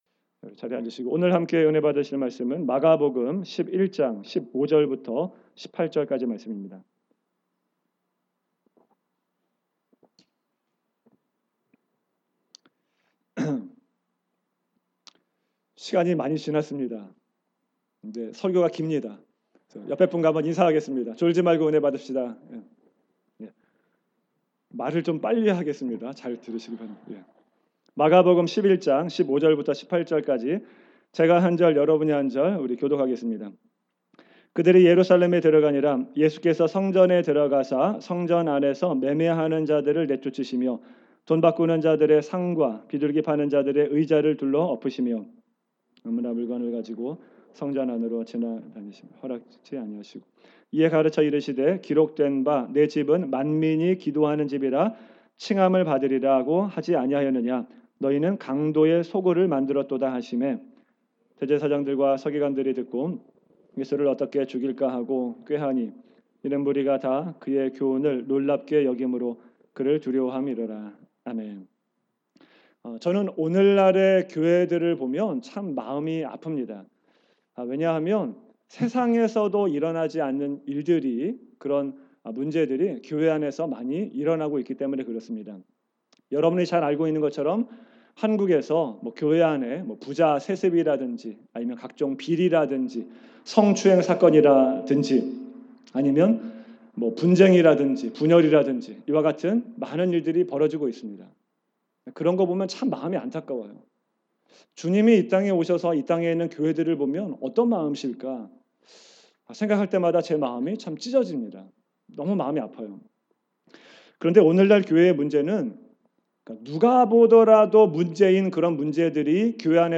2019년 주일설교